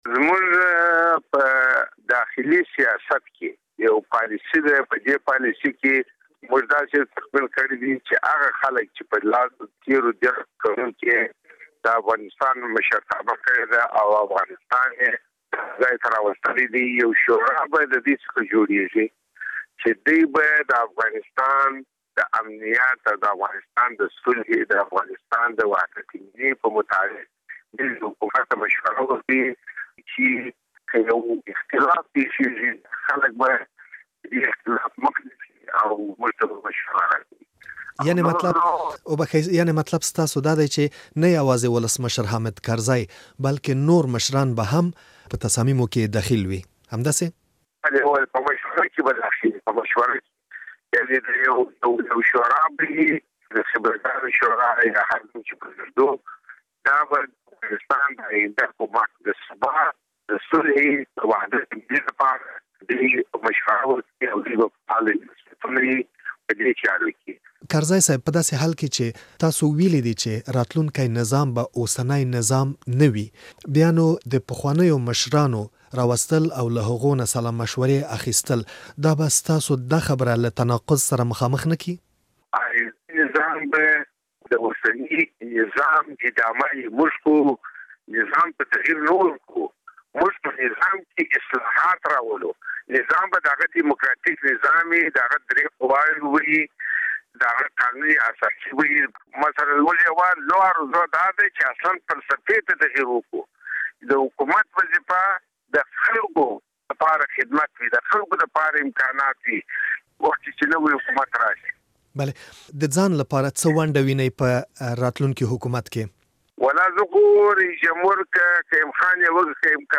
له محمود کرزي سره مرکه